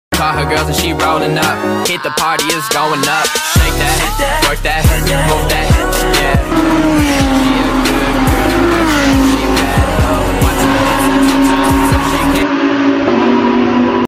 Wang Yibo's private racing practice at the Shanghai International Circuit